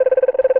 cartoon_electronic_computer_code_03.wav